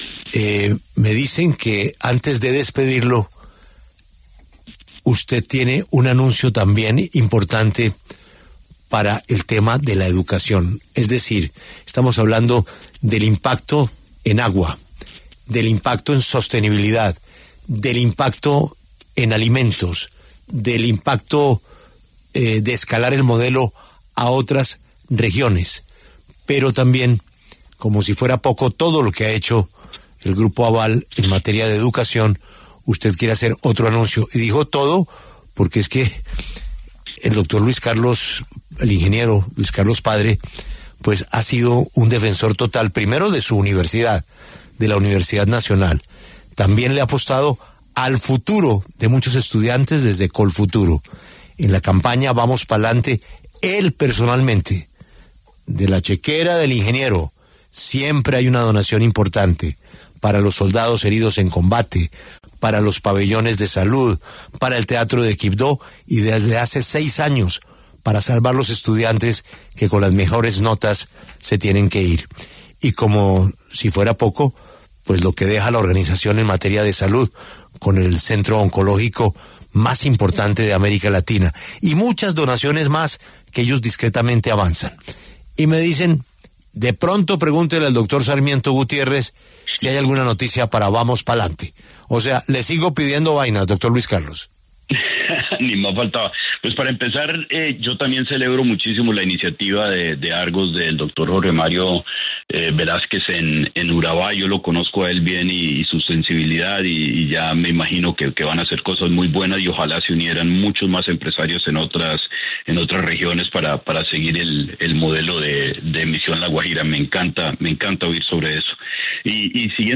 El empresario Luis Carlos Sarmiento Gutiérrez, presidente del Grupo Aval, se pronunció en La W acerca del aporte de su empresa a la campaña Pa’lante Colombia.